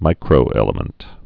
(mīkrō-ĕlə-mənt)